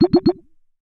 Do Do Do Alert.wav